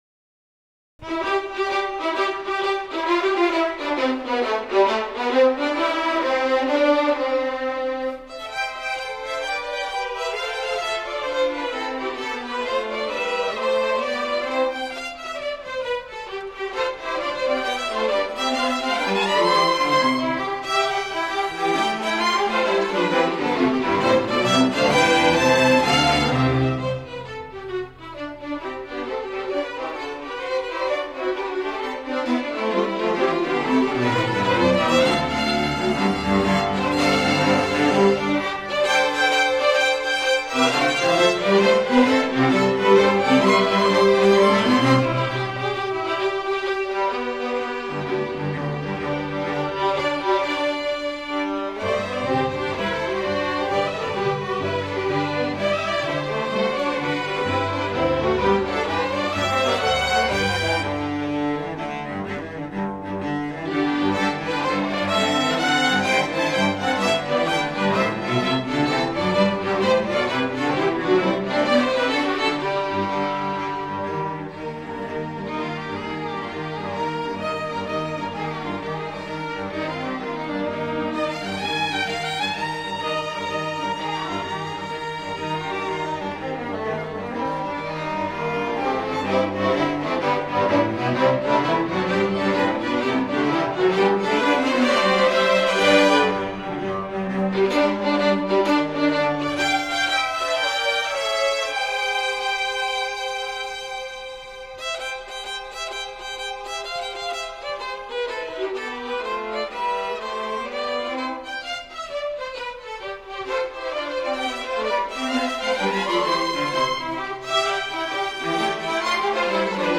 CONDUCTING AUDIO SAMPLES
Lied Middle School Orchestra, Las Vegas, Nevada